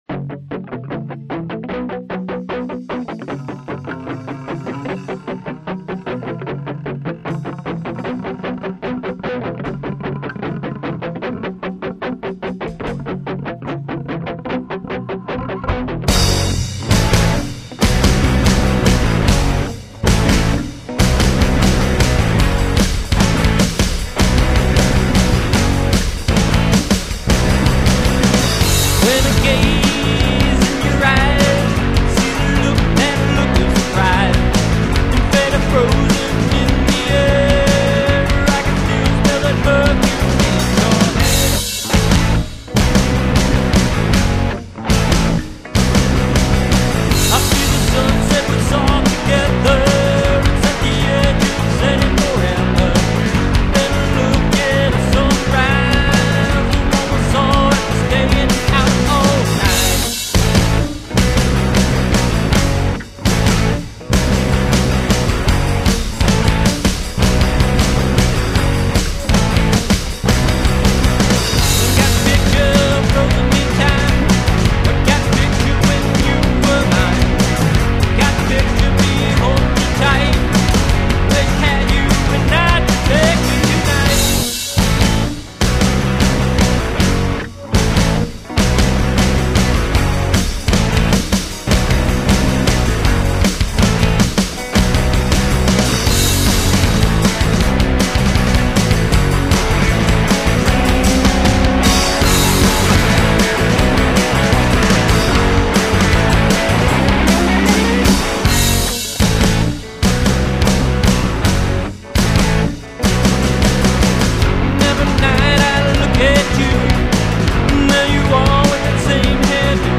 a Rock n' Roll Trio